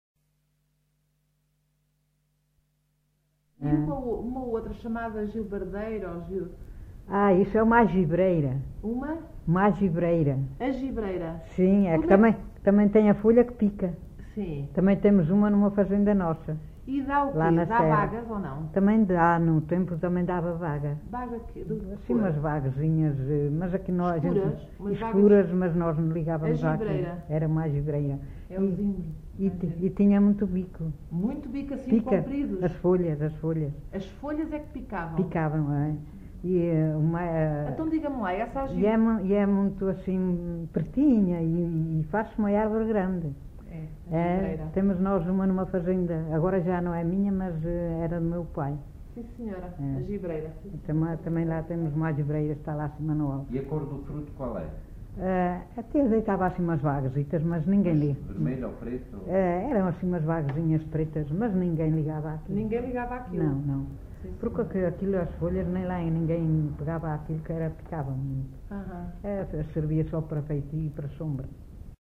Assanhas (Figueiró da Serra), excerto 14
LocalidadeAssanhas (Celorico da Beira, Guarda)